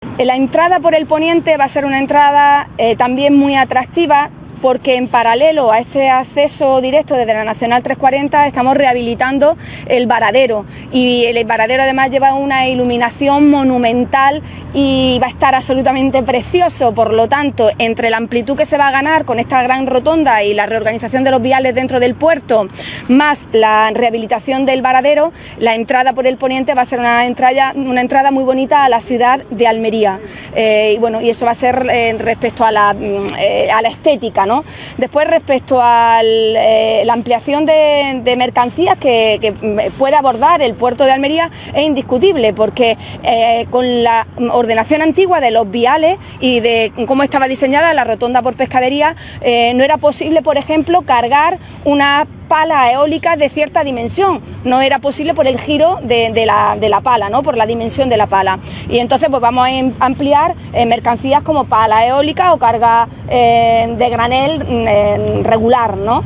ROSARIO-SOTO-PRESIDENTA-AUTORIDAD-PORTUARIA.wav